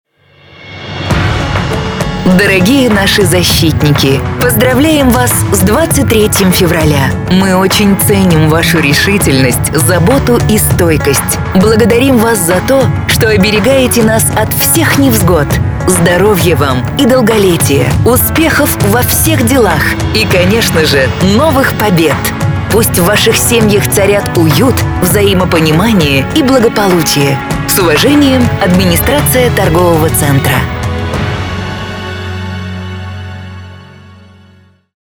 029_Поздравление с 23 февраля_женский.mp3